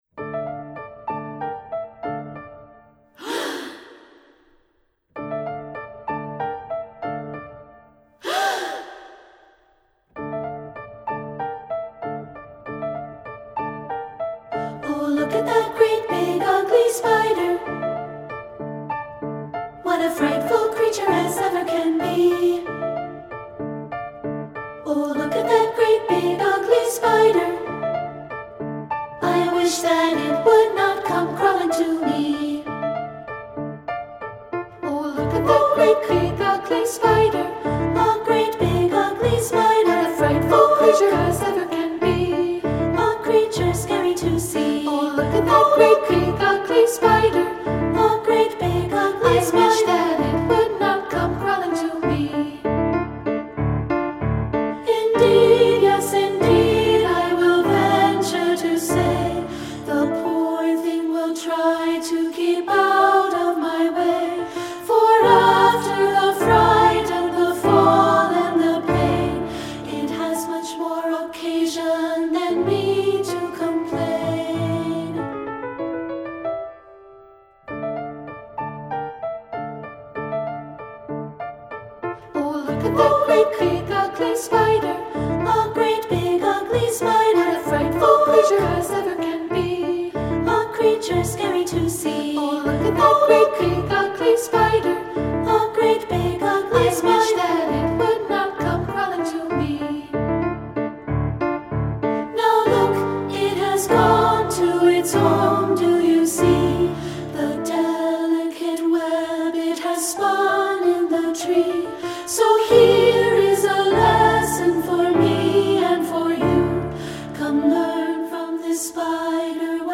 Voicing: Two-part